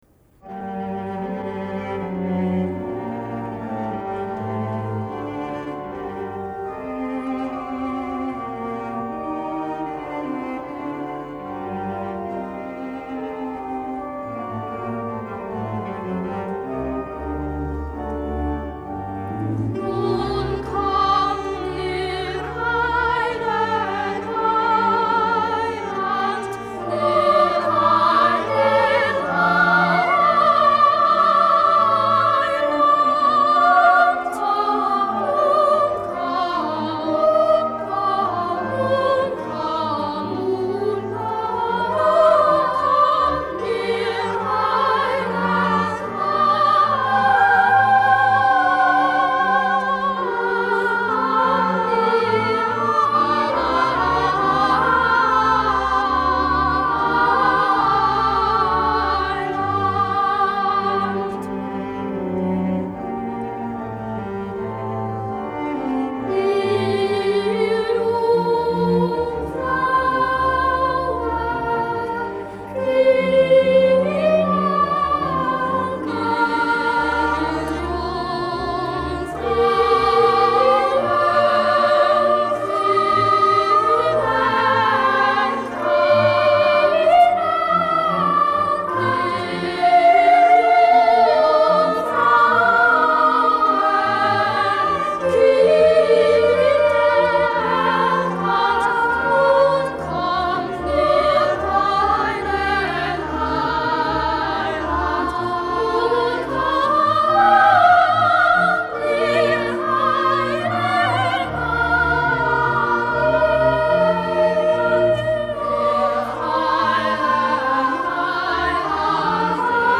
These are performances historically and musically unique, sung with power and passion.
THOMANERCHOR & Gewandhaus Orchestra – Günther RAMIN – Vol.2